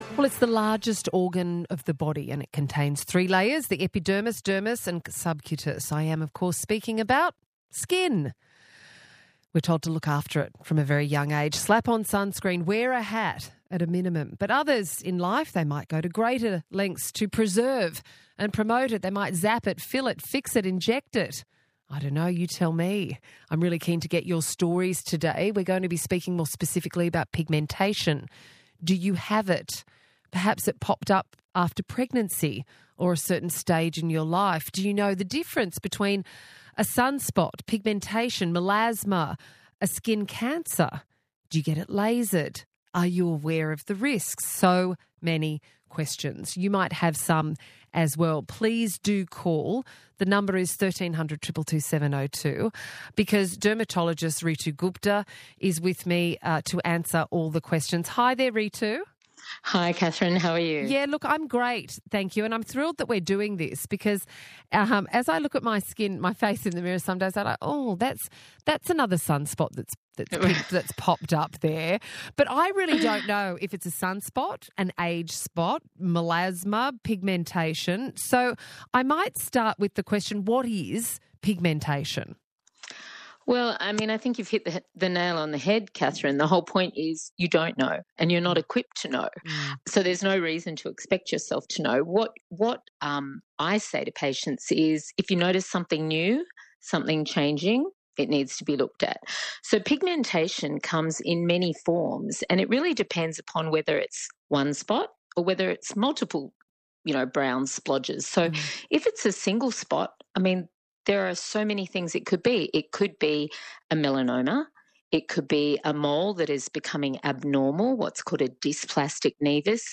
Calls and texts from listeners about skin pigmentation and various issues poured in